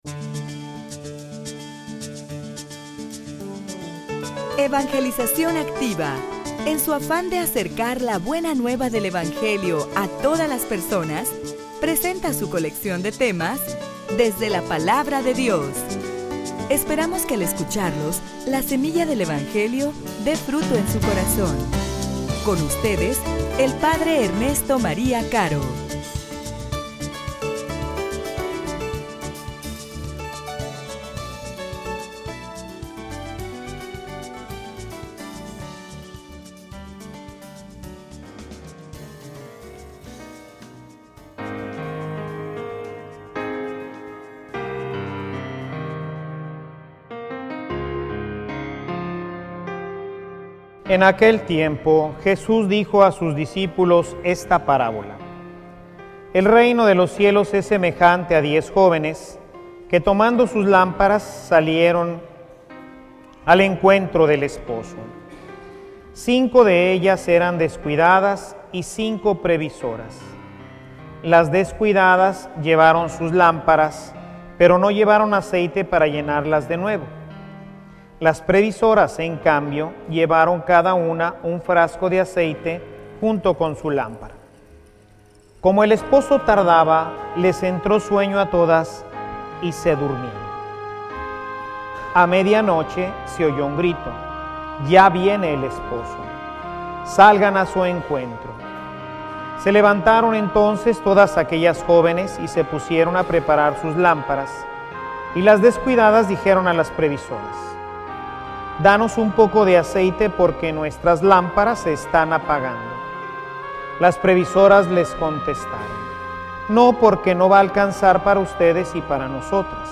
homilia_Que_no_se_apague_tu_luz.mp3